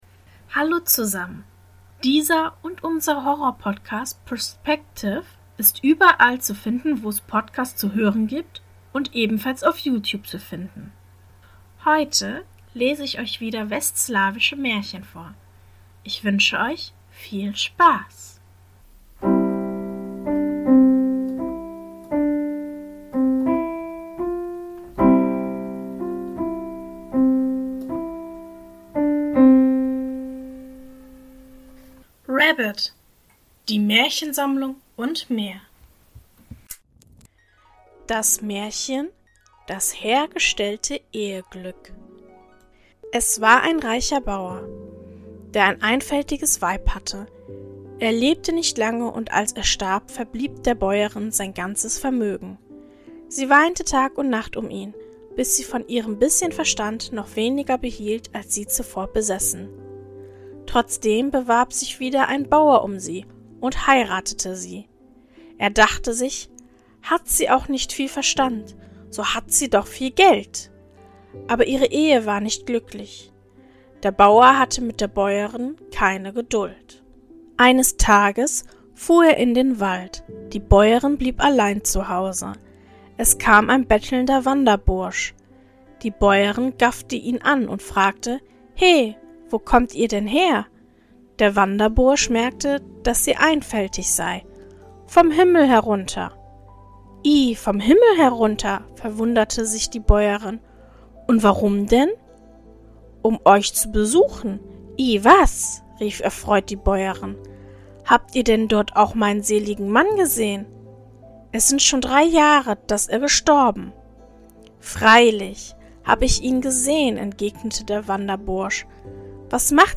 In der heutigen Folge lese ich Folgendes vor: 1.